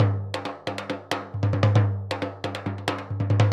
Surdo 2_Merengue 136-1.wav